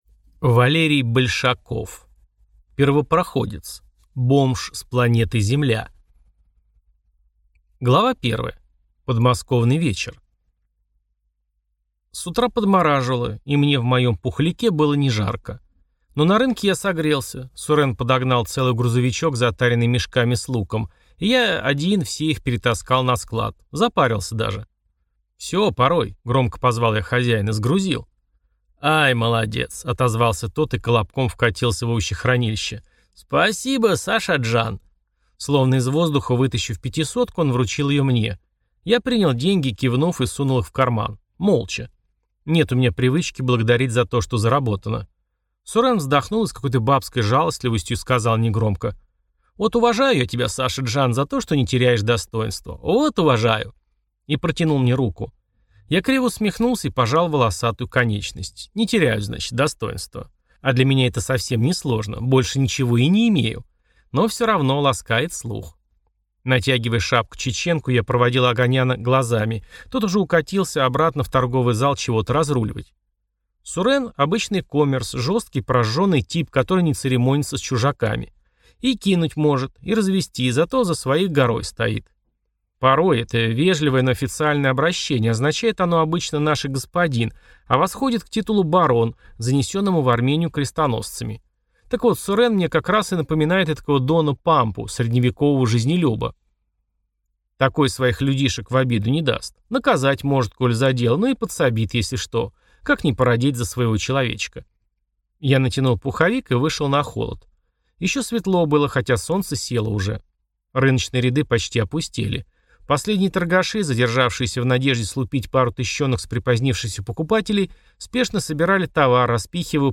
Аудиокнига Первопроходец. Бомж с планеты Земля | Библиотека аудиокниг